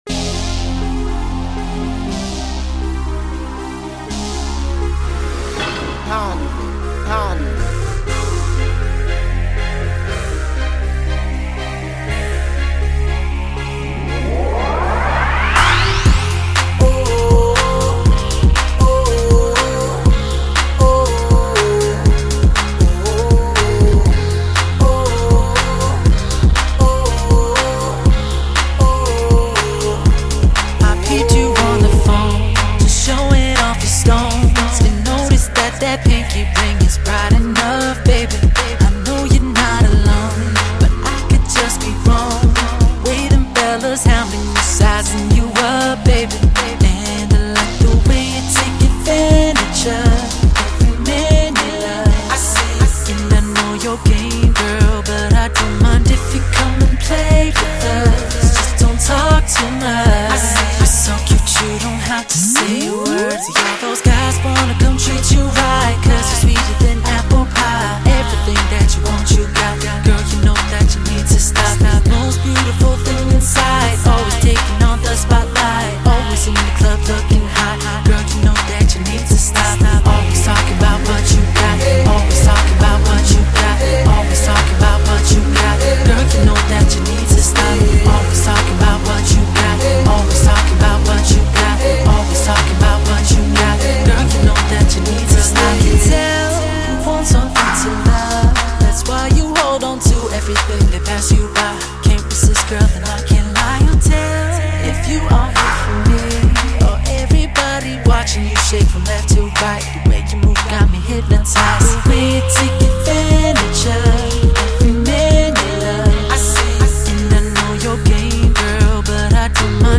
Главная » Файлы » Музыка » Танцевальная
RnB